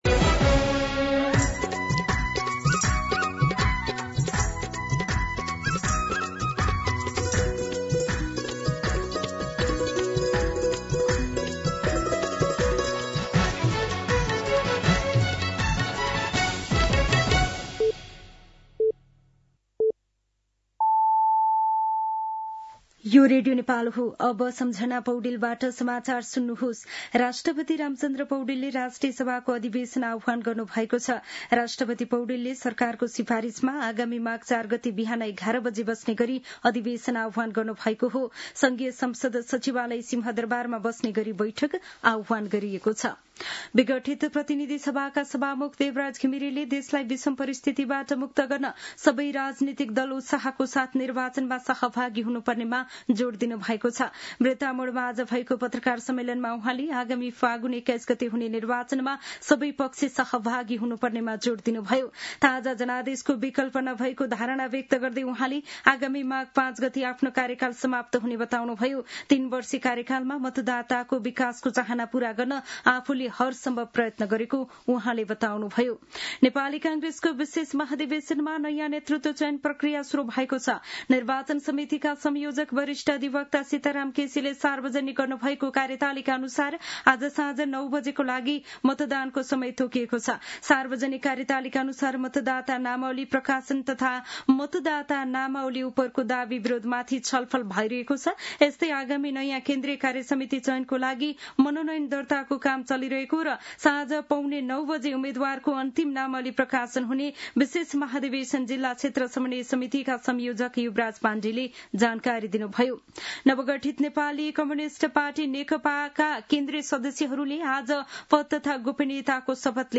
दिउँसो ४ बजेको नेपाली समाचार : २९ पुष , २०८२